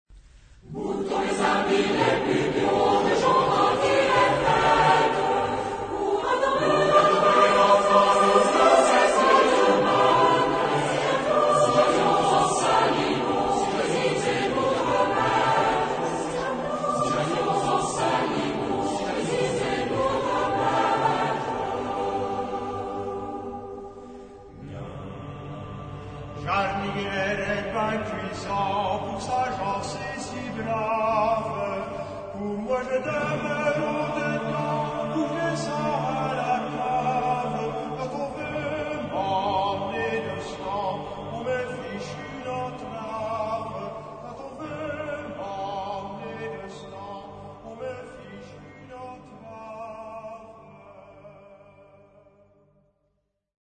Genre-Style-Forme : Populaire ; Profane
Caractère de la pièce : joyeux ; vivant
Type de choeur : SATB  (4 voix mixtes )
Solistes : Soprano (1) / Ténor (1)  (2 soliste(s))
Tonalité : sol majeur